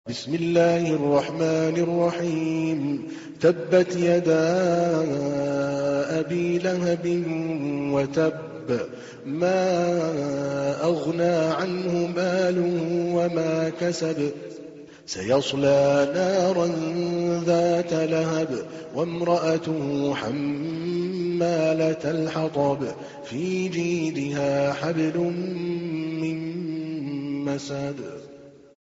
تحميل : 111. سورة المسد / القارئ عادل الكلباني / القرآن الكريم / موقع يا حسين